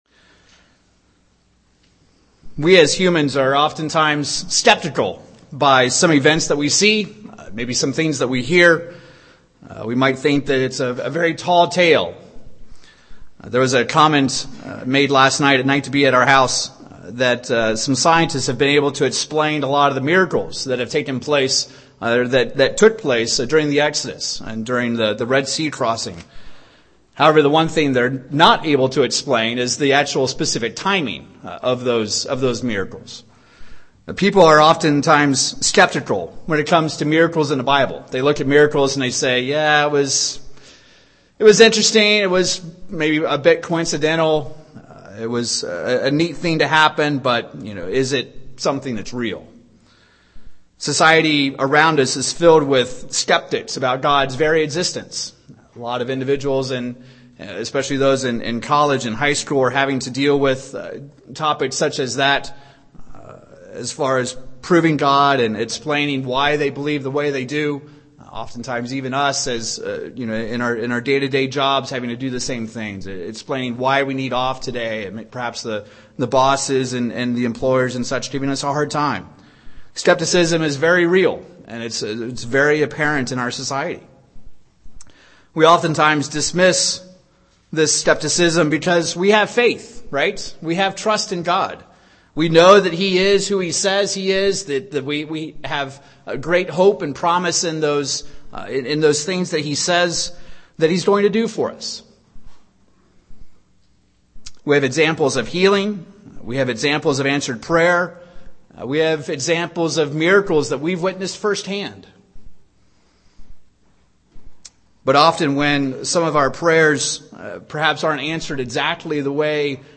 Given in Wichita, KS
UCG Sermon Studying the bible?